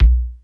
Kick_105.wav